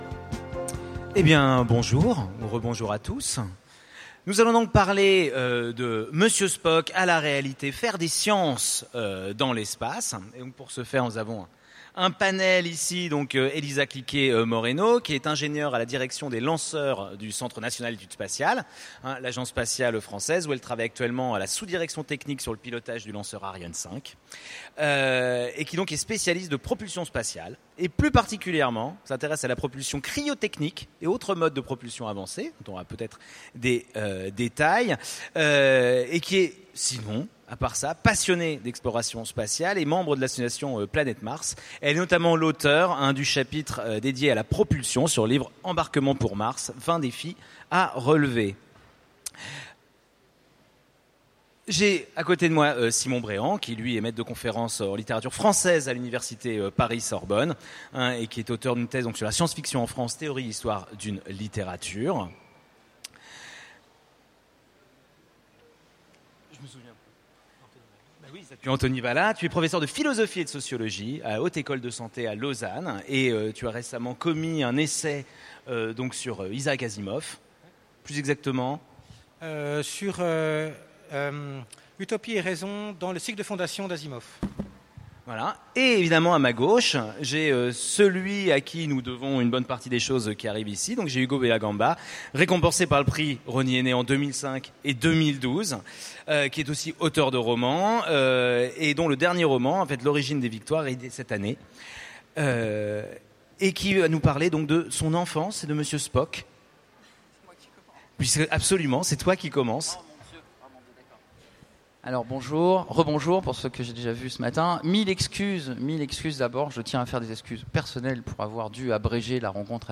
Utopiales 2015 : Conférence Faire des sciences dans l’espace